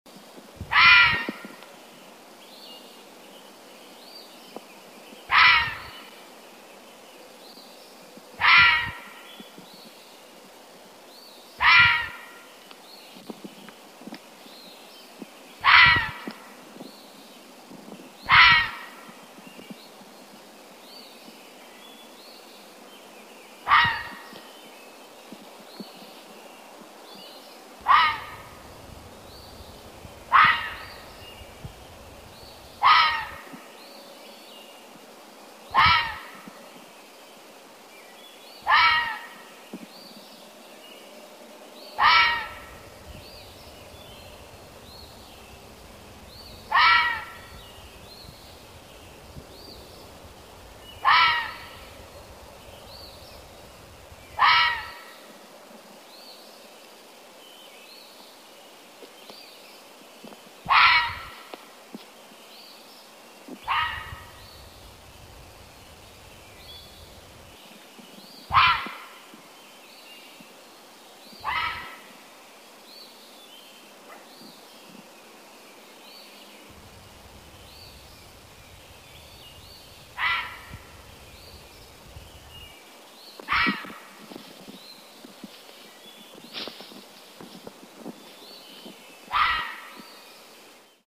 Fisher Cat Sounds ringtone free download
Sound Effects